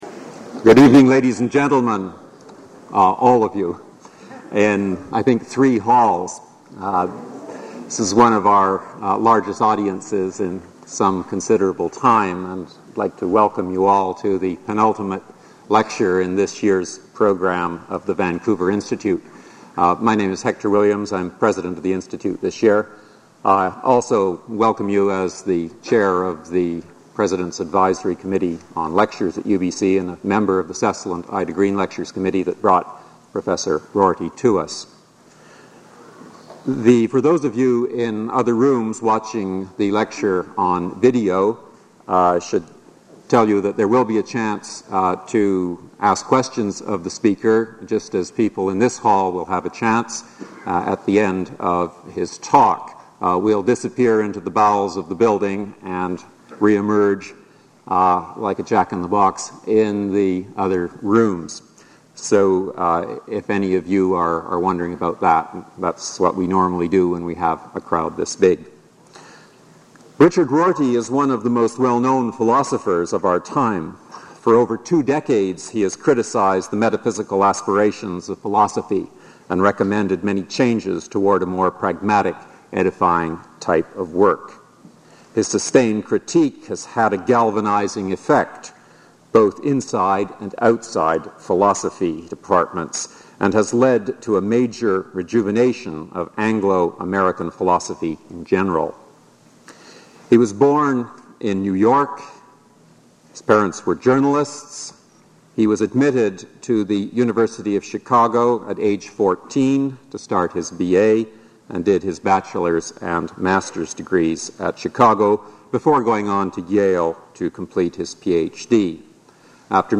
Item consists of a digitized copy of an audio recording of a Cecil and Ida Green lecture delivered at the Vancouver Institute by Richard Rorty on March 19, 1994.